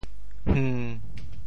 hng1.mp3